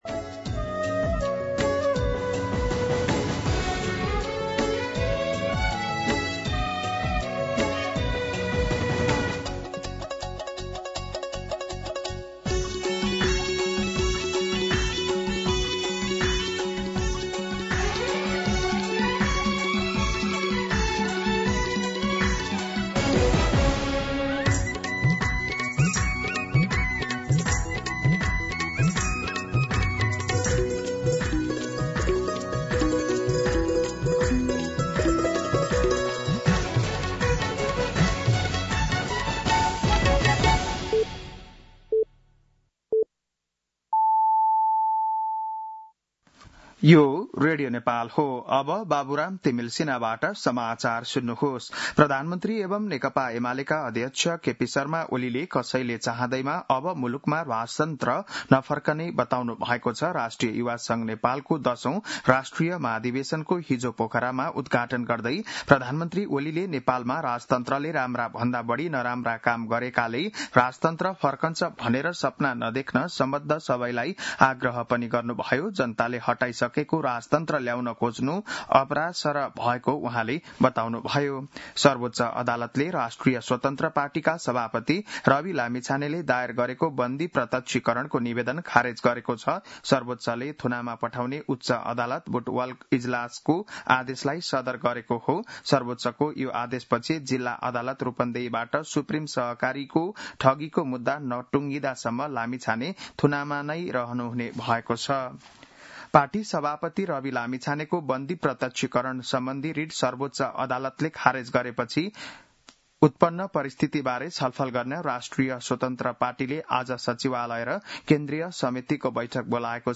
बिहान ११ बजेको नेपाली समाचार : १० जेठ , २०८२
11-am-Nepali-News-5.mp3